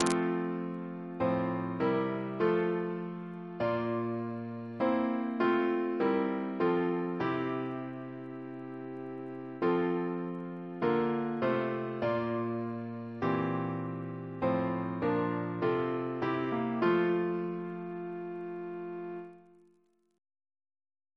CCP: Chant sampler
Double chant in F Composer: William Russell (1777-1813) Reference psalters: PP/SNCB: 19